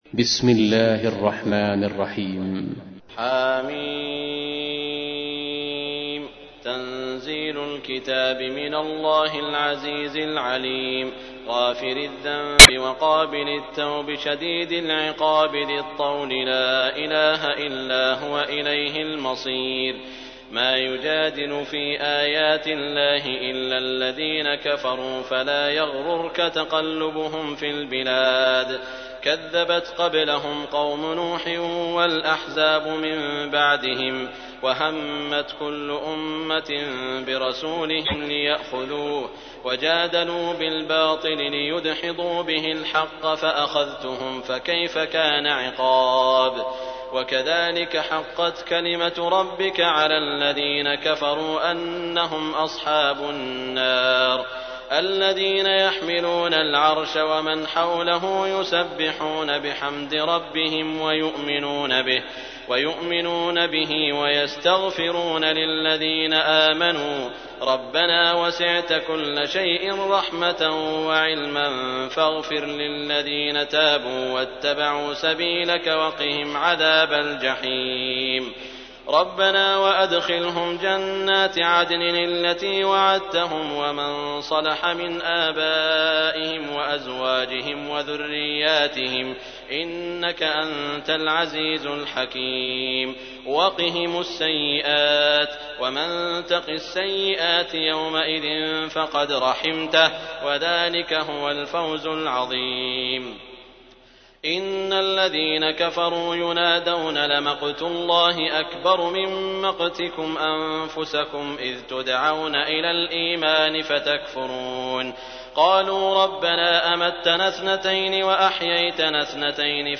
تحميل : 40. سورة غافر / القارئ سعود الشريم / القرآن الكريم / موقع يا حسين